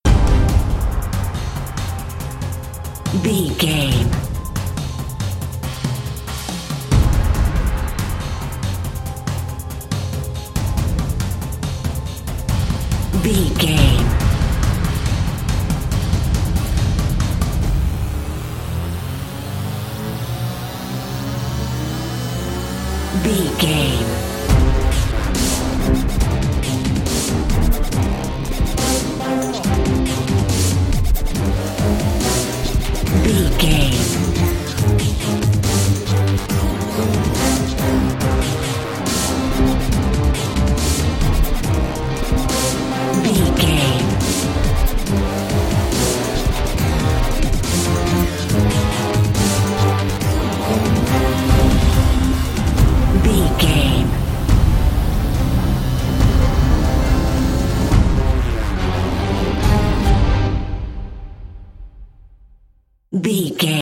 Epic / Action
Aeolian/Minor
strings
drums
synthesiser
brass
orchestral
orchestral hybrid
dubstep
aggressive
energetic
intense
bass
synth effects
wobbles
driving drum beat